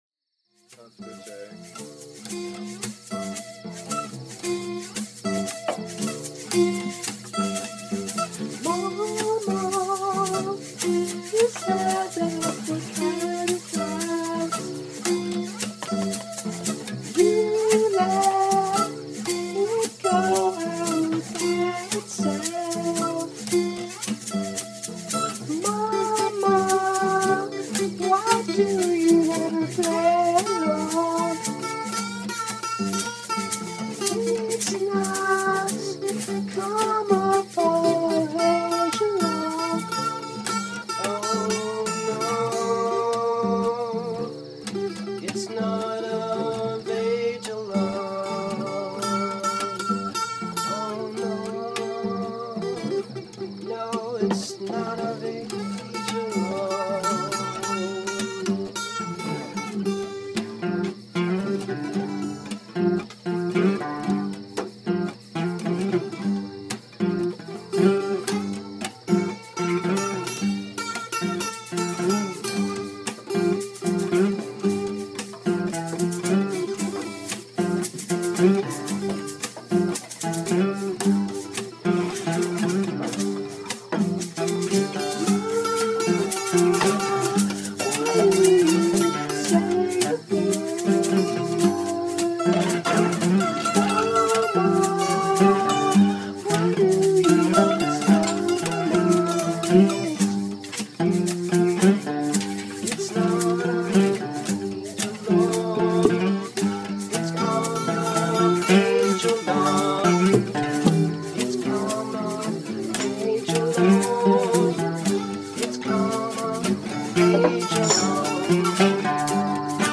ALL MUSIC IS IMPROVISED ON SITE